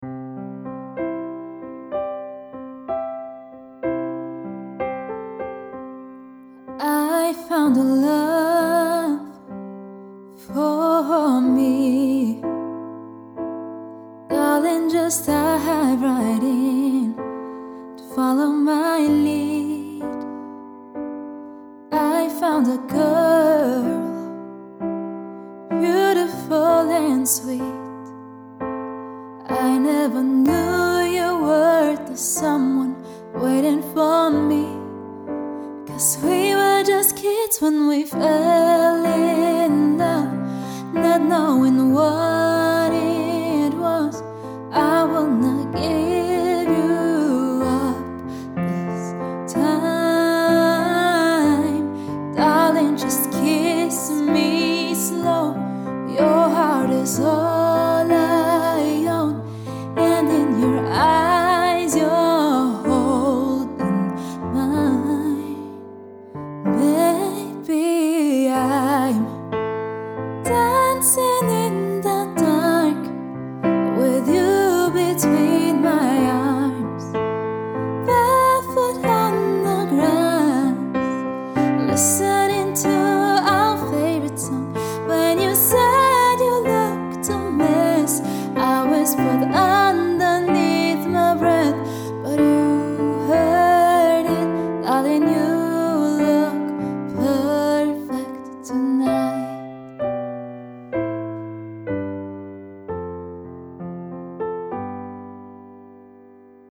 Duo